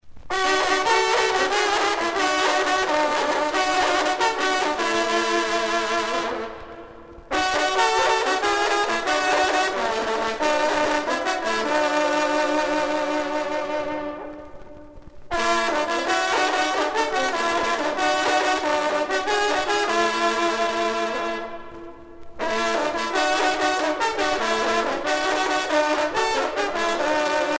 vénerie
Pièce musicale éditée